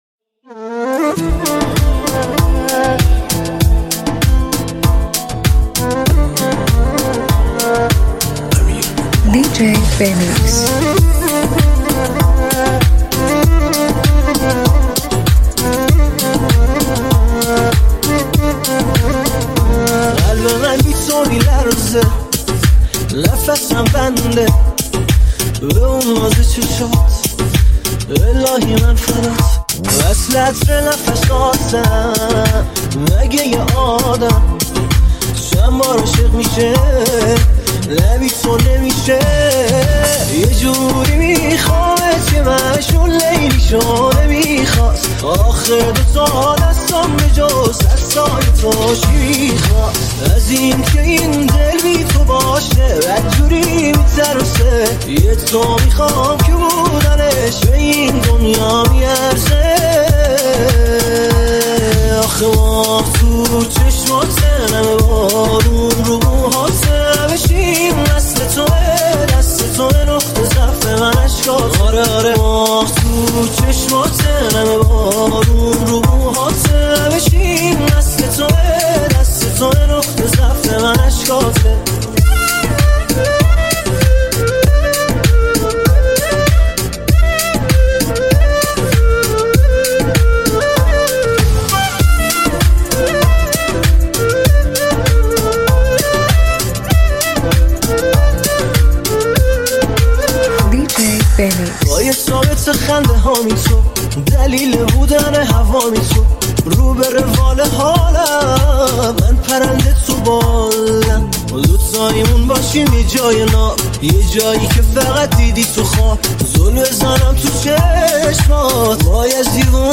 این ریتم فوق‌العاده و پرانرژی
یه بمب انرژی با یه بیس سنگین و یه ریتم دیوانه‌کننده‌ست